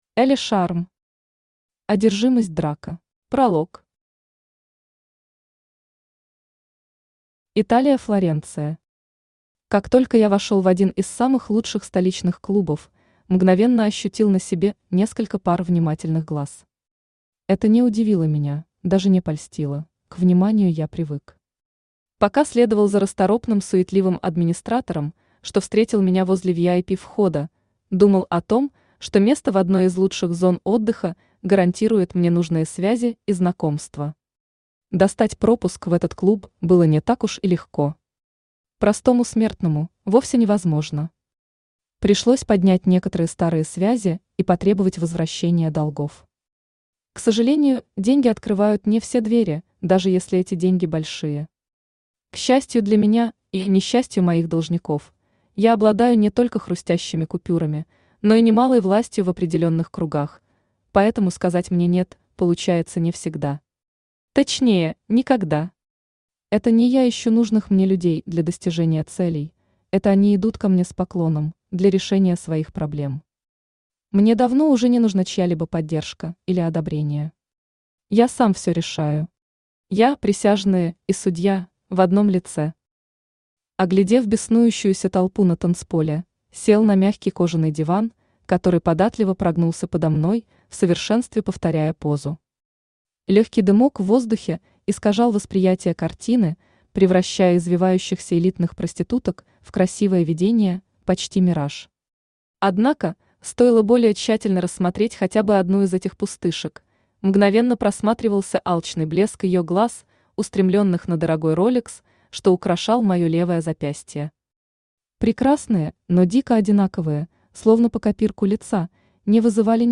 Аудиокнига Одержимость Драко | Библиотека аудиокниг
Aудиокнига Одержимость Драко Автор Элли Шарм Читает аудиокнигу Авточтец ЛитРес.